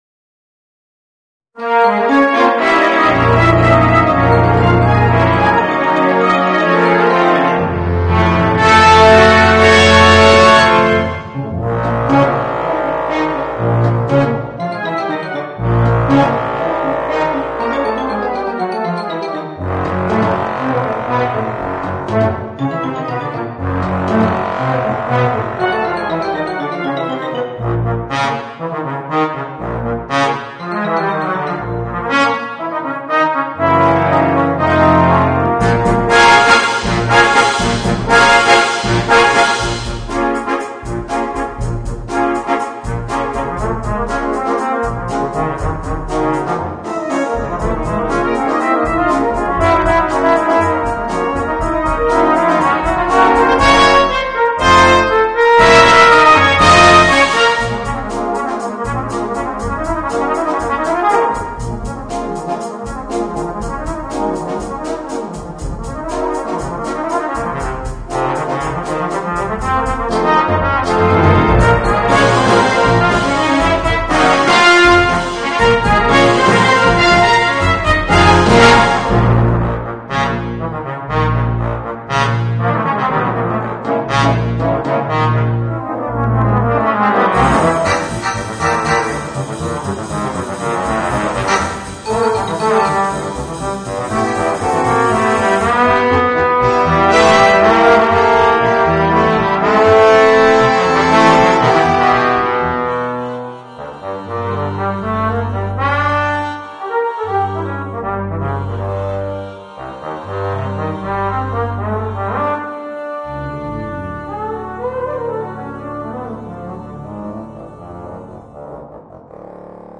Voicing: Bass Trombone and Brass Band